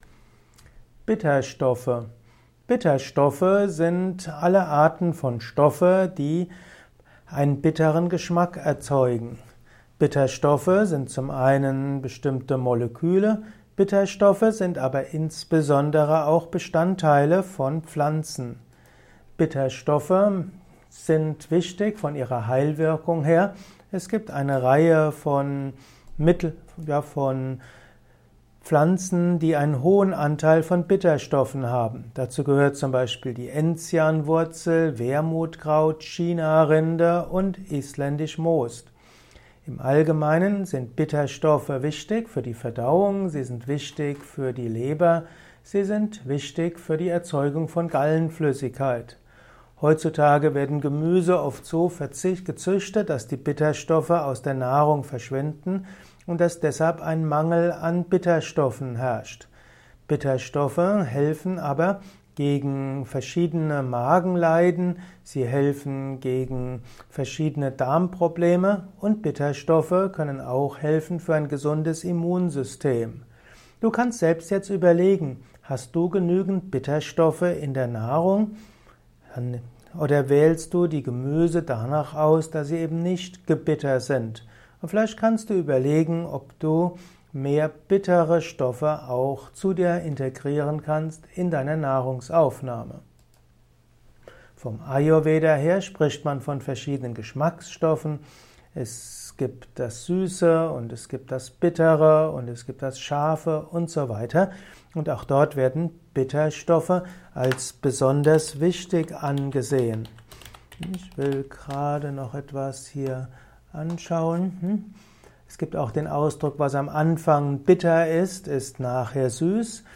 Kompakte Informationen zu Bitterstoffe in diesem Kurzvortrag